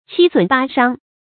七損八傷 注音： ㄑㄧ ㄙㄨㄣˇ ㄅㄚ ㄕㄤ 讀音讀法： 意思解釋： 形容損傷慘重。